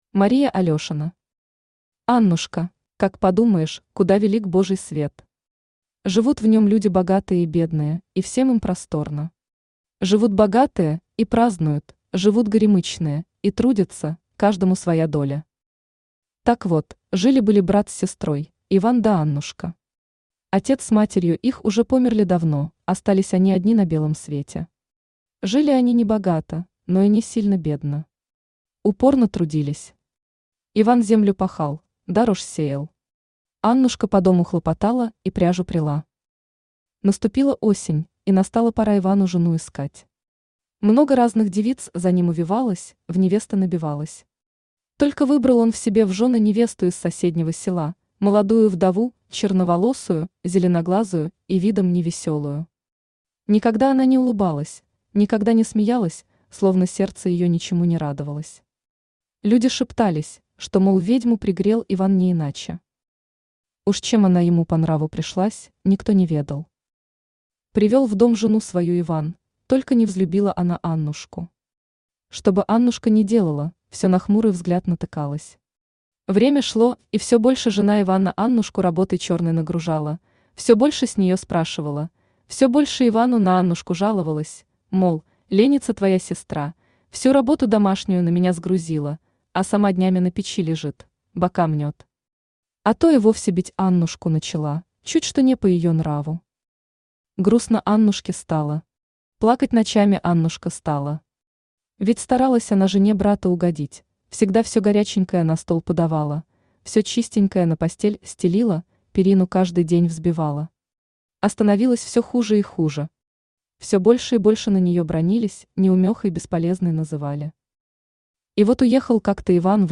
Аудиокнига Аннушка | Библиотека аудиокниг
Aудиокнига Аннушка Автор Мария Алешина Читает аудиокнигу Авточтец ЛитРес.